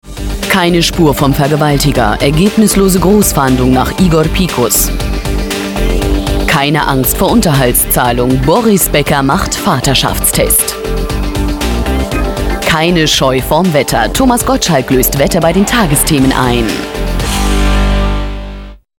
deutsche Sprecherin für Dokus, Feature, Synchronisation, Over-Voice, Hörbuch, Magazine, Trailer, Werbung
Sprechprobe: Werbung (Muttersprache):